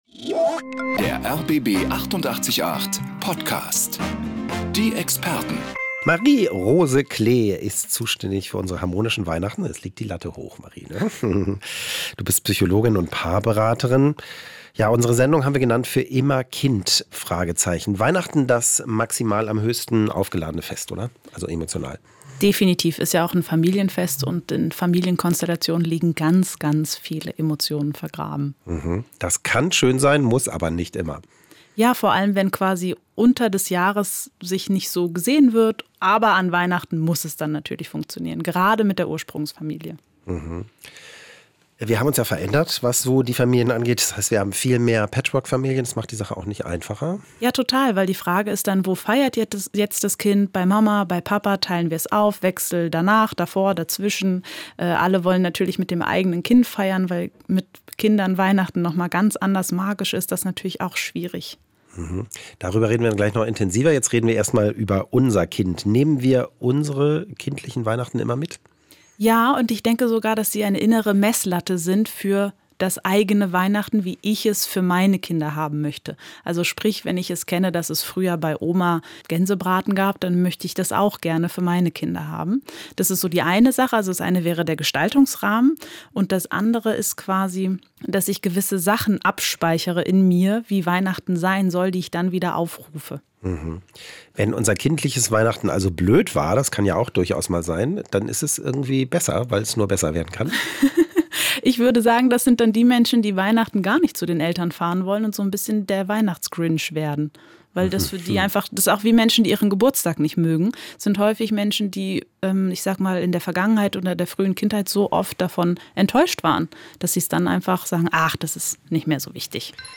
Ein Gespräch über Rituale, Kindheitszauber und die Kunst, Weihnachten gelassen zu bleiben und zu genießen.